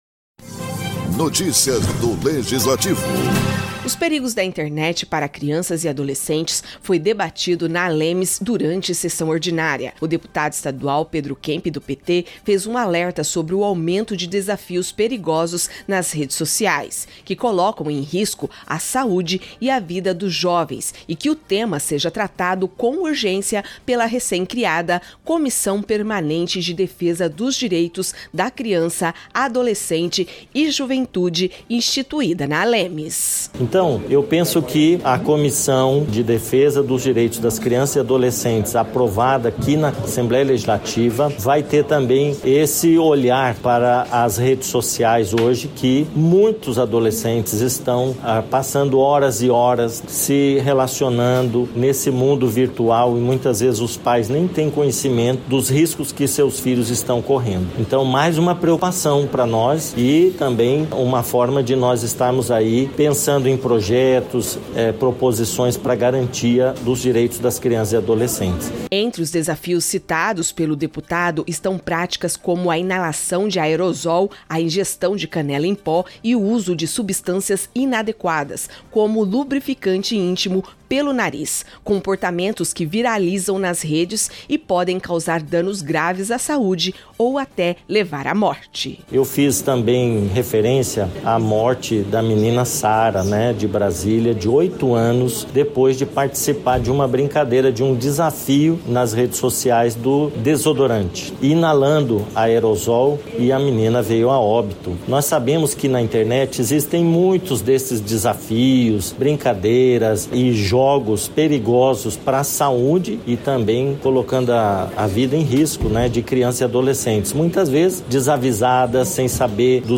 Durante sessão na Assembleia Legislativa de Mato Grosso do Sul (ALEMS), o deputado Pedro Kemp (PT), destacou o aumento de desafios perigosos nas redes sociais, como inalação de aerossol e ingestão de substâncias, alertando para os riscos à saúde e à vida de crianças e adolescentes.